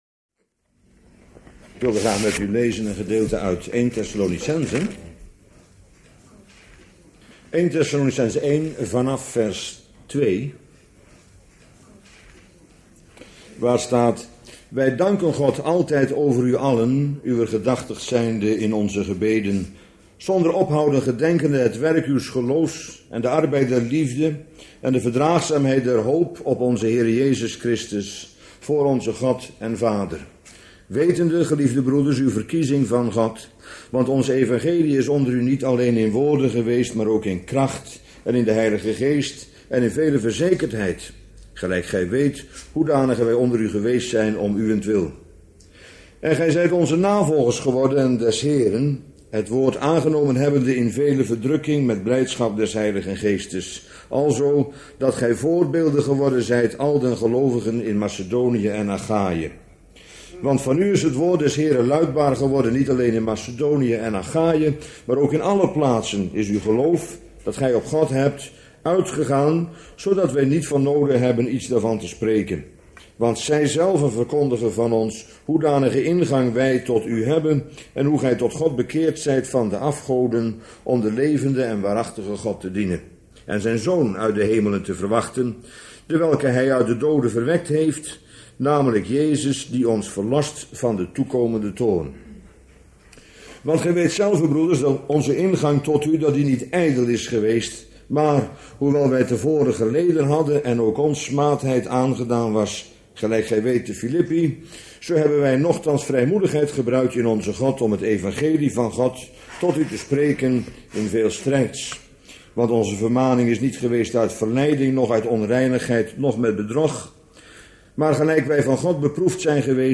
Bijbelstudie lezing